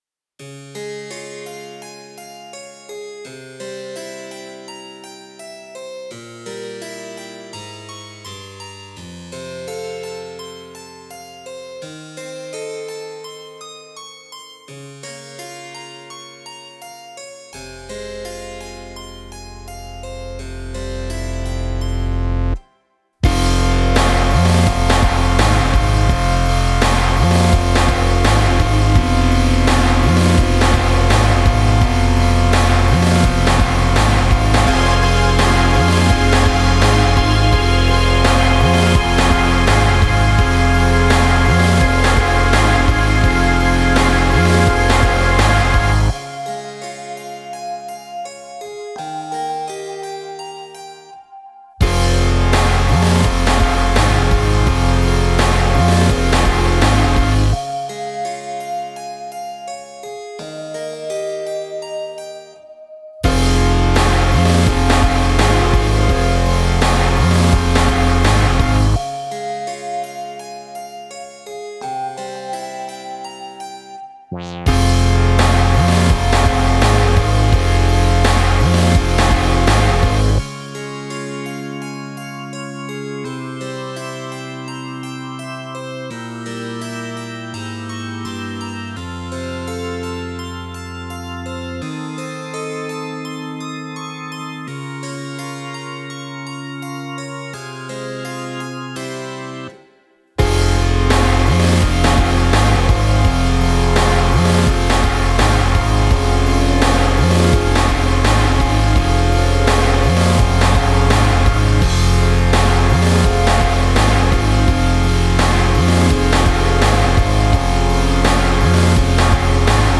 Instrumental version: